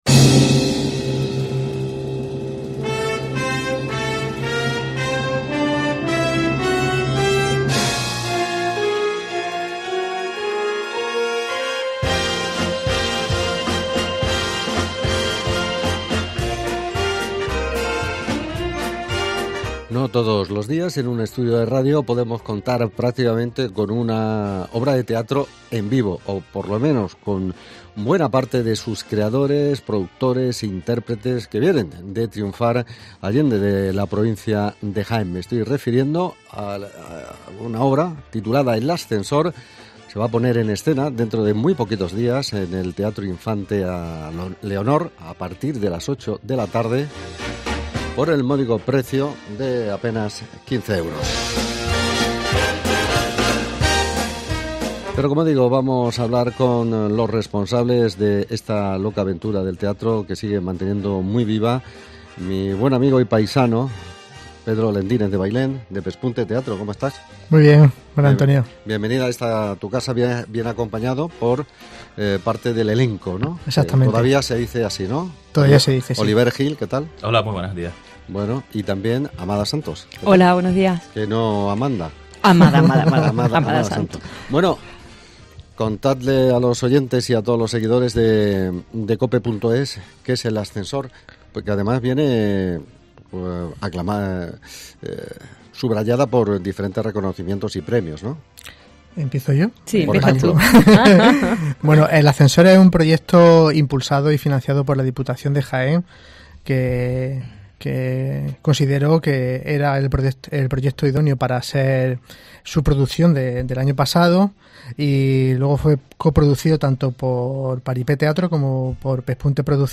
Hablamos con el elenco de El Ascensor